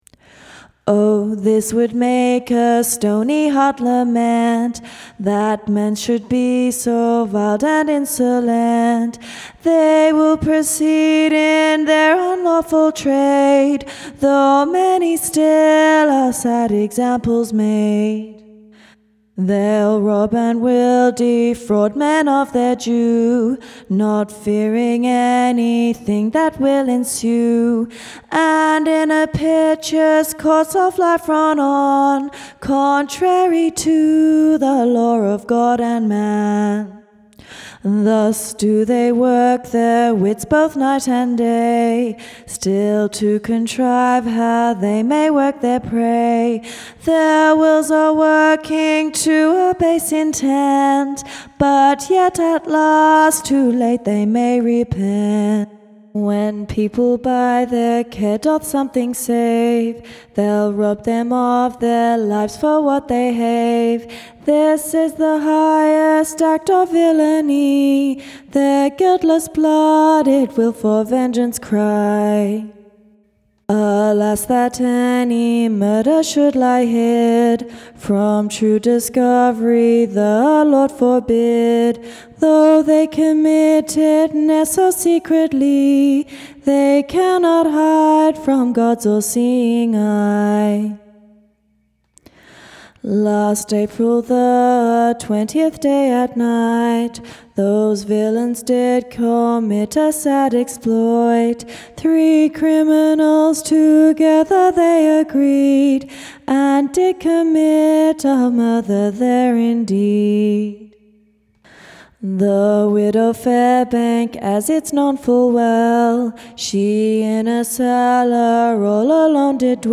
Execution Ballads